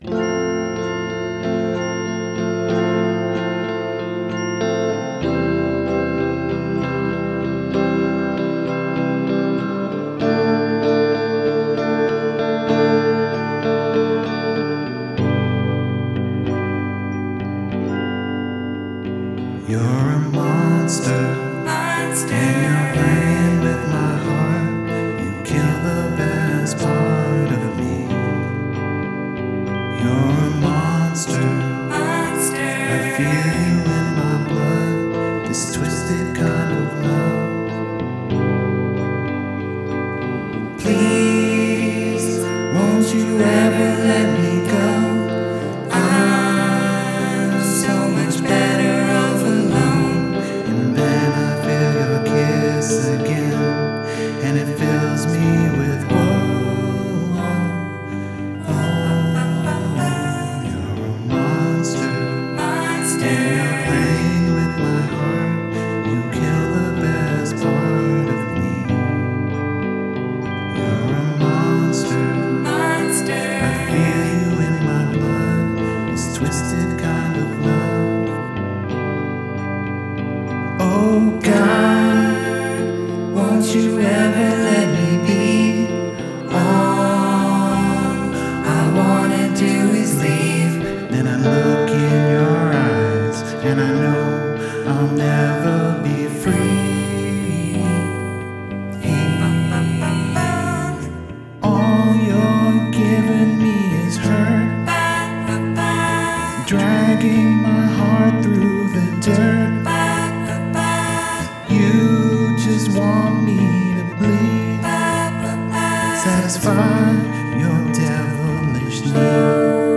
our two instruments were guitar & Omnichord